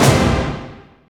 Index of /90_sSampleCDs/Optical Media International - Sonic Images Library/SI2_SI FX Vol 3/SI2_Gated FX 3